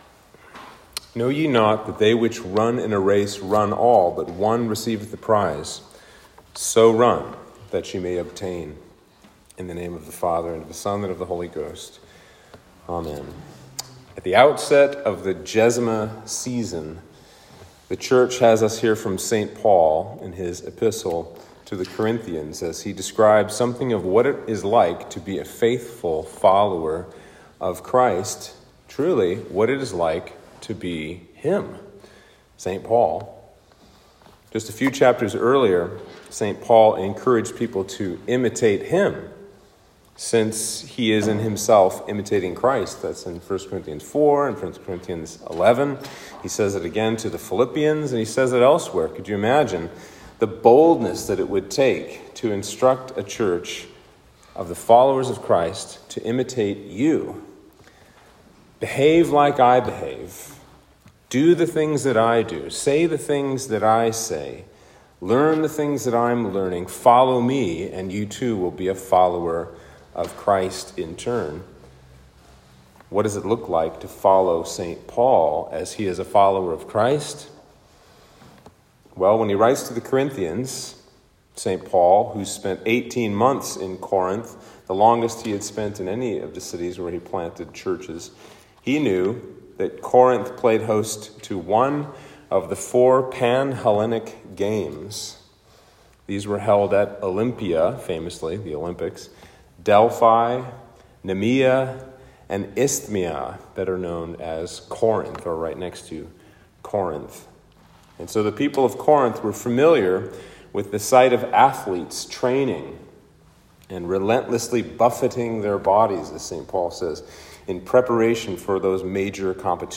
Sermon for Septuagesima